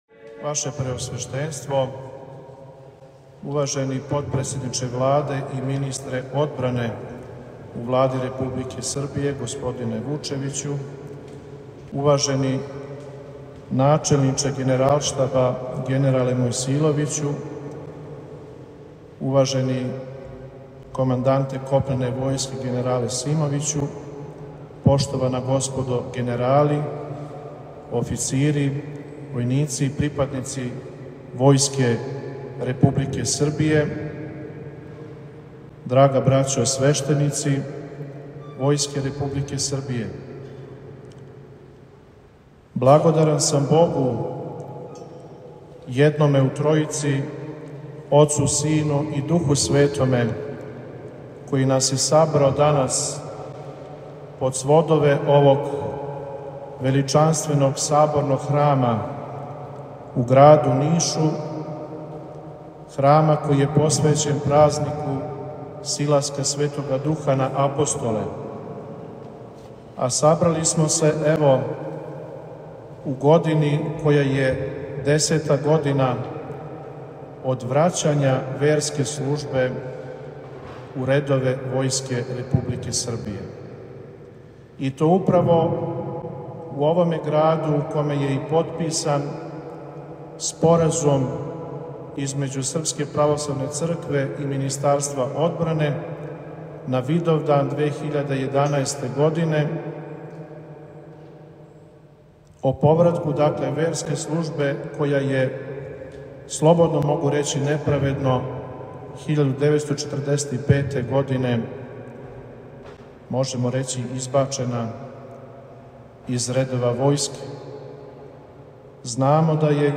У четвртак, 11. маја 2023. године, Његово Преосвештенство Епископ нишки и војни г. Арсеније началствовао је Светом Архијерејском Литургијом у нишком Саборном храму Силаска Светога Духа на Апостоле. Звучни запис беседе Преосвећеном Епископу саслуживао је Његово Преосвештенство Епископ липљански г. Доситеј, викар Његове Светости Патријарха српског г. Порфирија и нови Епископ војни.